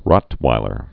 (rŏtwīlər, rôt-)